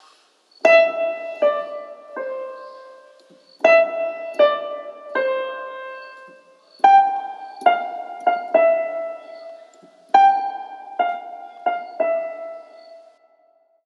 Tags: theater